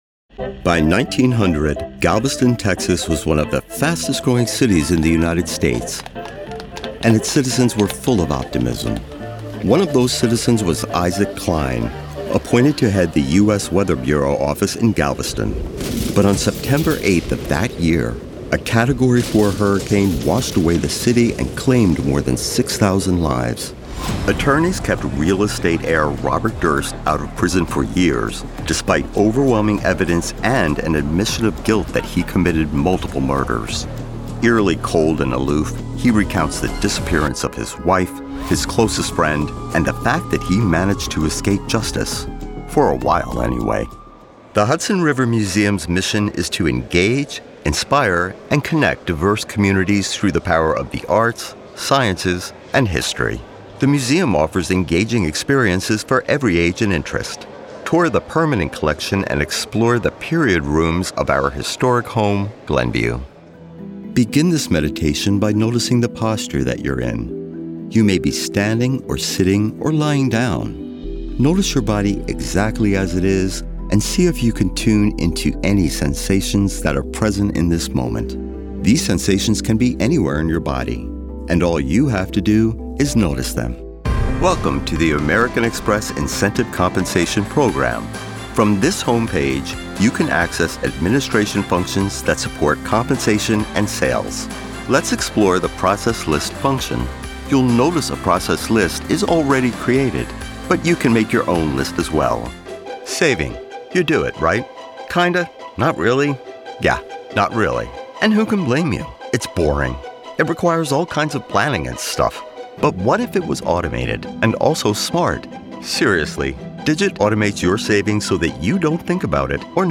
Male
English (North American)
Adult (30-50), Older Sound (50+)
My commercial and narrative style is friendly, knowledgeable, relatable and confident, while my promo style is upbeat and dynamic with a wide range of tones...high energy, gritty, dark or mysterious!
Full Narration Demo
0214Narration_Demo.mp3